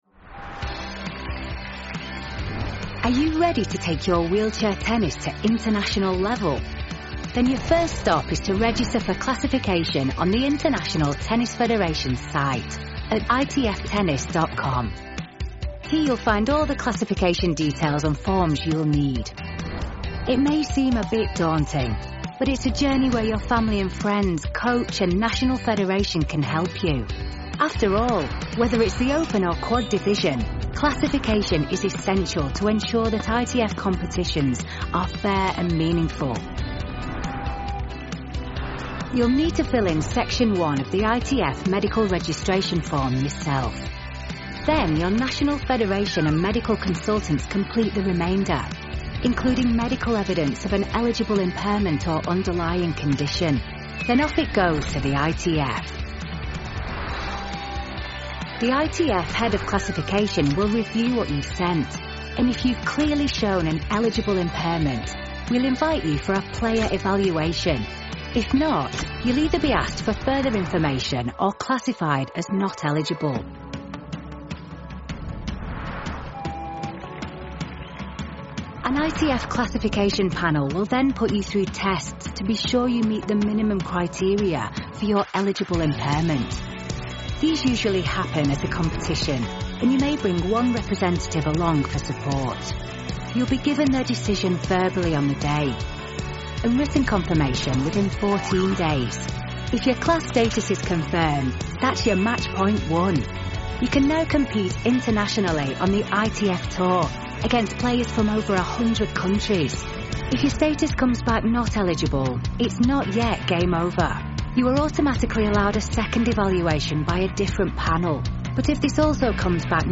Rapide, fiable et naturellement conversationnelle, elle offre une voix off professionnelle qui captive et captive.
Vidéos explicatives
* Cabine de son spécialement conçue, isolée et traitée acoustiquement
* Micro et protection anti-pop Rode NT1-A